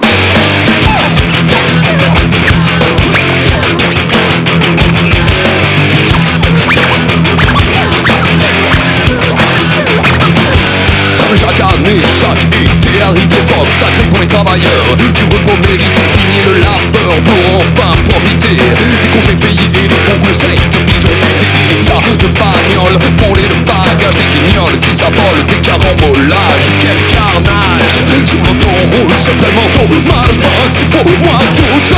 Plus directs, les nouvelles compos ont su se
teindre de la puissance du métal sans les redites du genre.